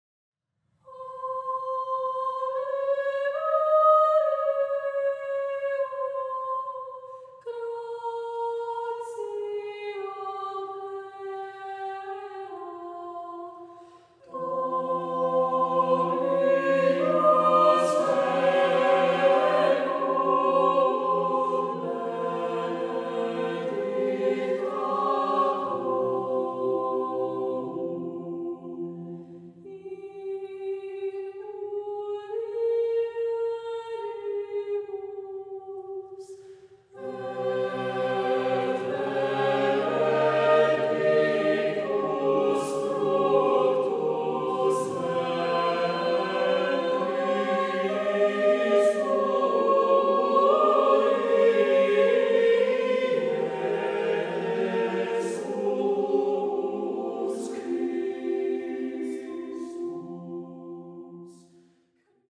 MIXED CHORUS
(all are SATB, unless otherwise noted)
A Cappella